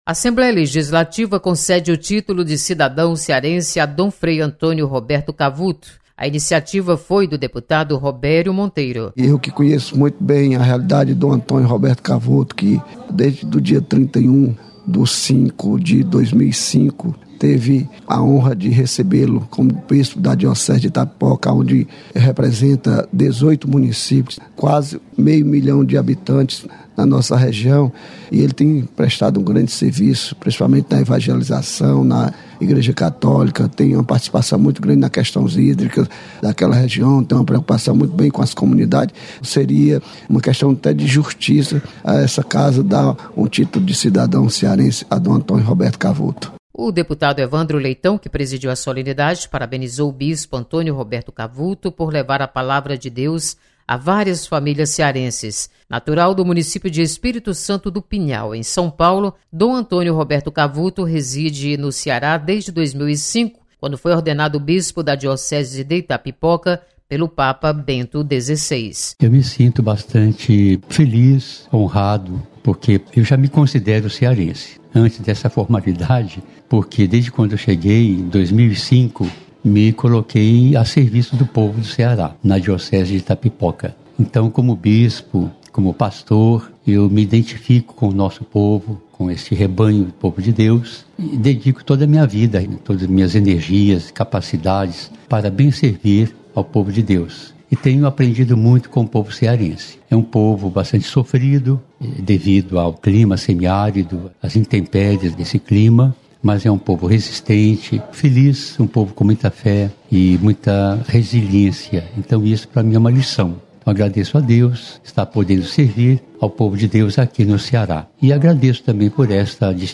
Dom Frei Antônio Roberto Cavuto recebe homenagem da Assembleia. Repórter